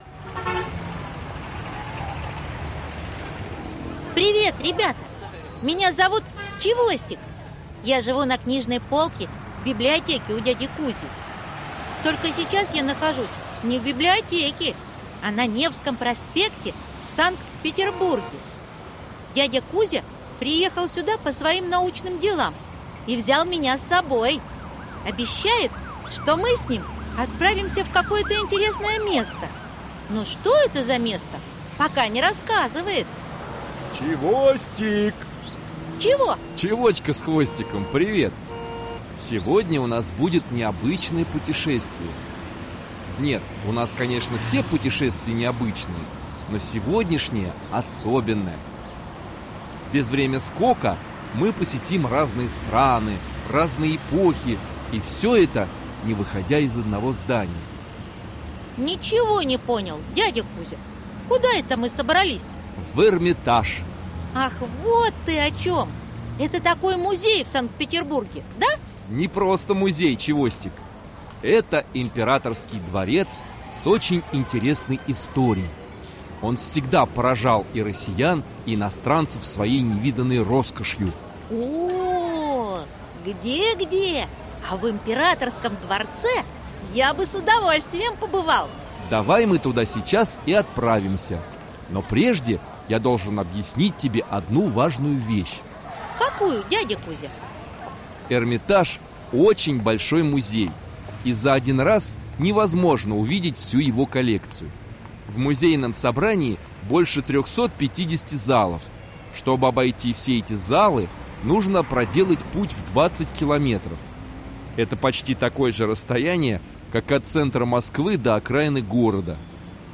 Аудиокнига Эрмитаж. Часть 1 | Библиотека аудиокниг